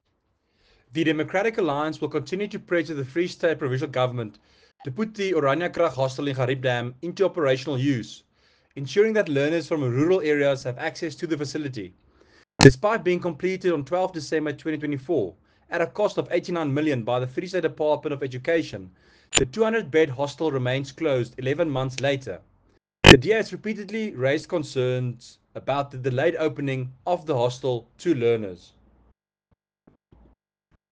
Afrikaans soundbites by Werner Pretorius MPL and